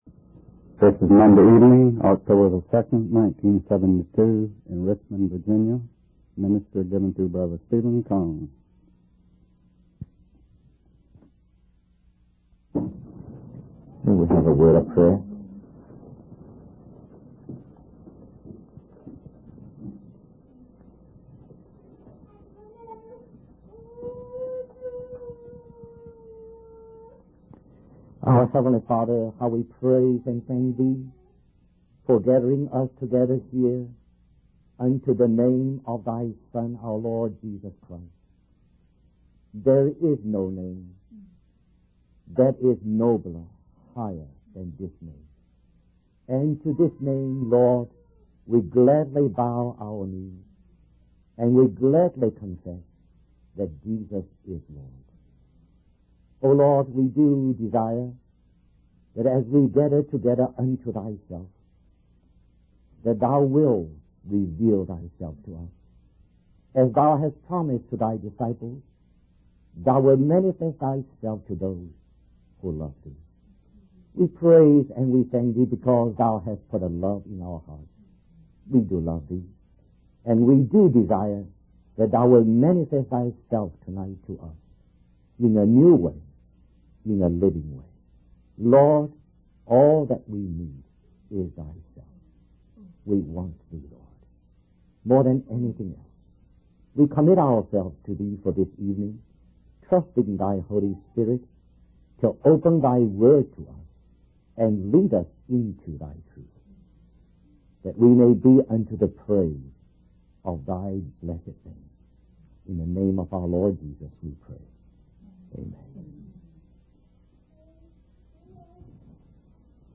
In this sermon, the preacher focuses on Acts 4:32, which describes the unity and generosity of the early church. The believers in the church were united in heart and soul, and they considered everything they owned to be common among them.